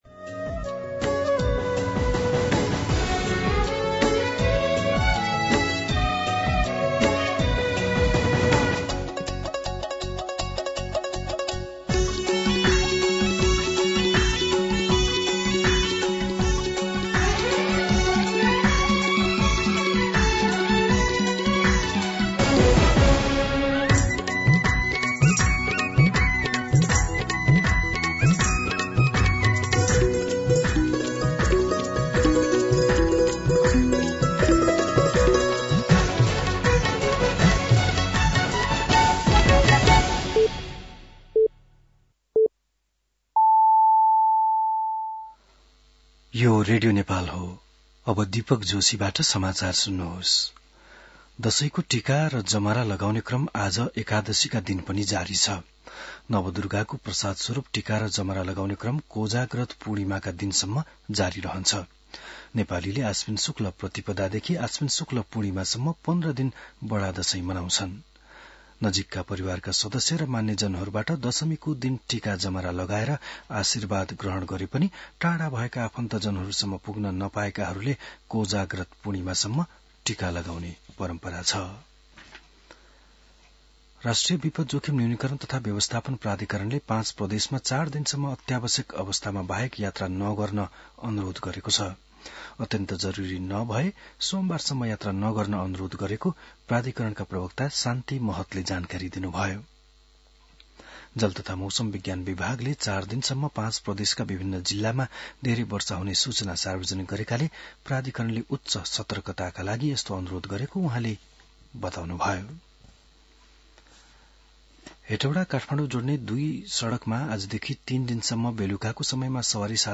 11-am-Nepali-News.mp3